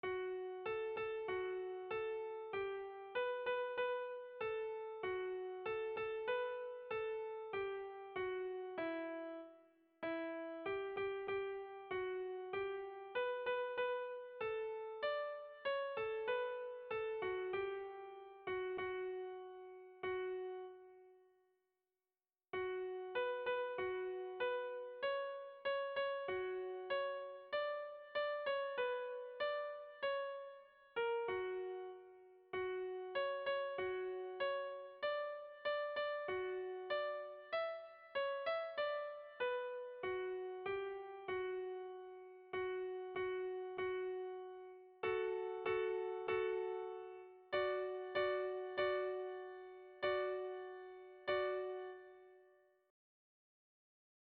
Kontakizunezkoa
Zortziko handia (hg) / Lau puntuko handia (ip)
ABDE